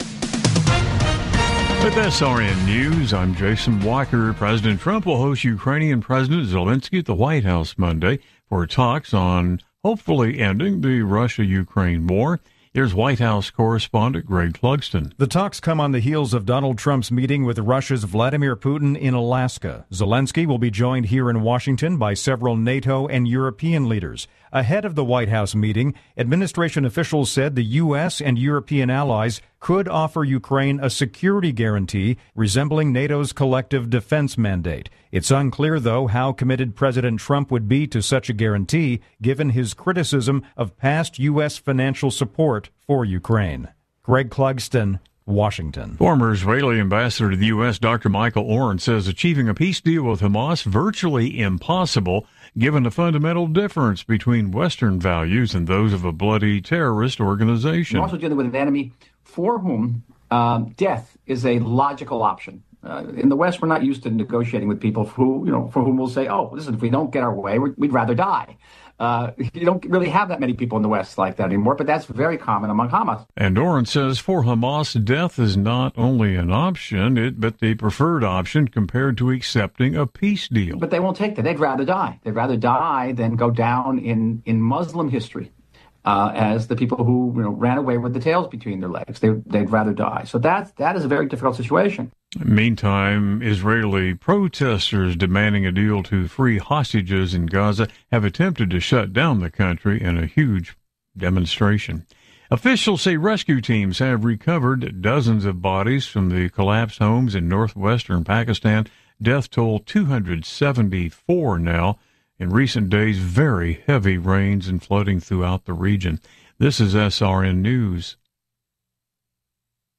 Top News Stories Aug 18, 2025 – 02:00 AM CDT